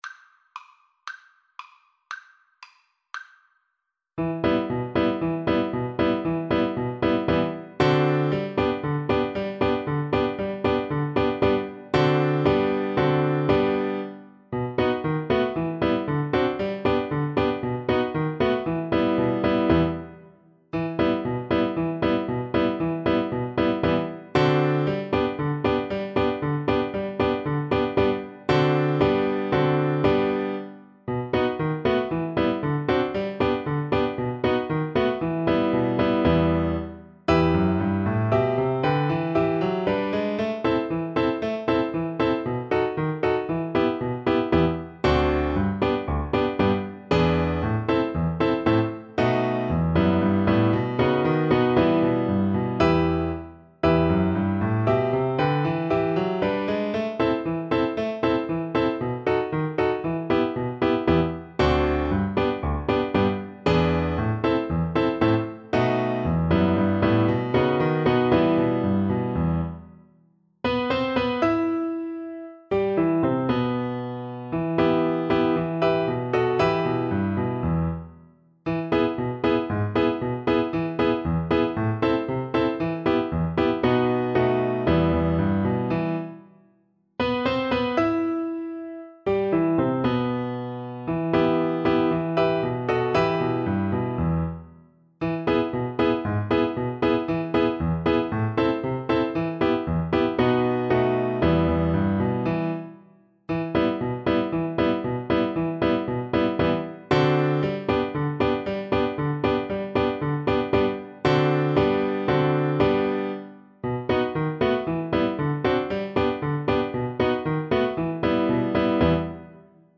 Allegro =c.116 (View more music marked Allegro)